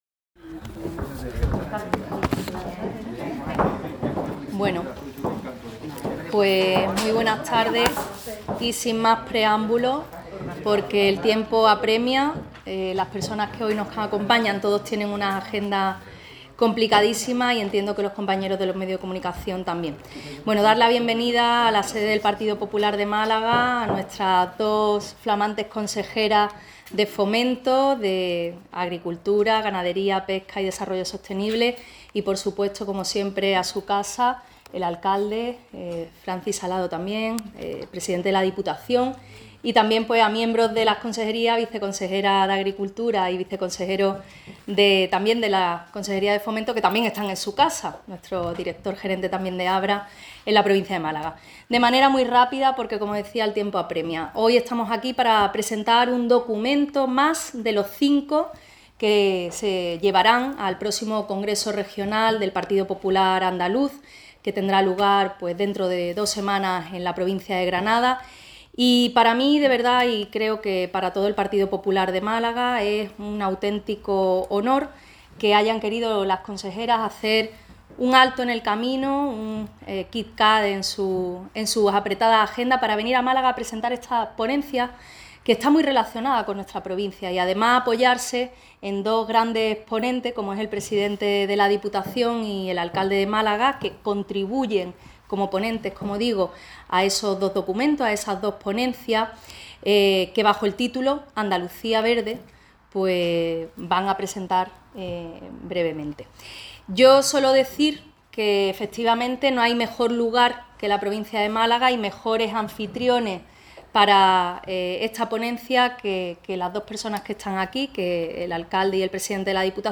En un acto en Málaga, las coordinadoras de la ponencia han reivindicado que “el patrimonio verde no pertenece a ninguna ideología política sino que es una obligación moral de todas las formaciones políticas”.